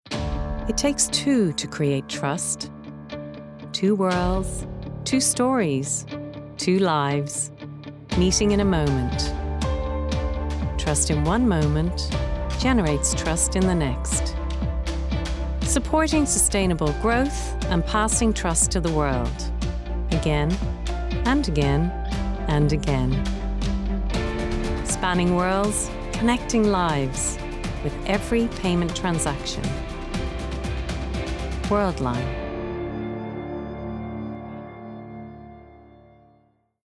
Rolls Royce Voice Over